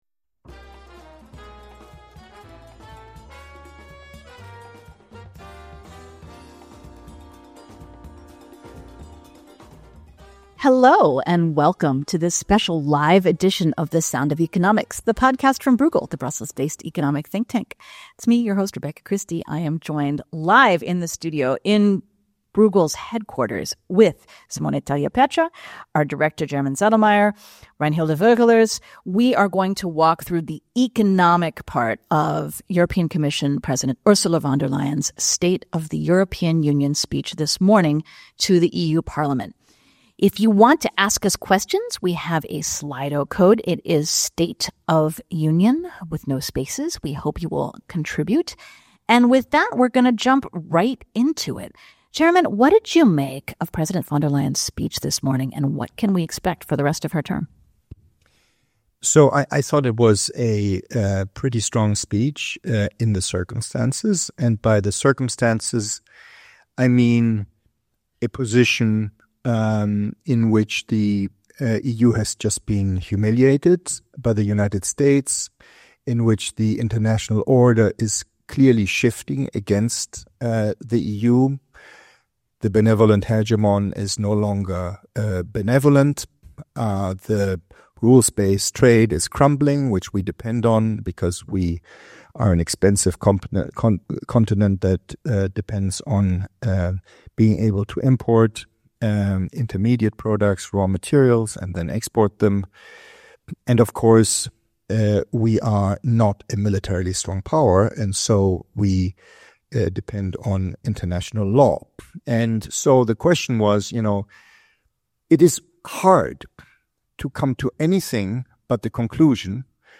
The Sound of Economics Live: The State of the European Union 2025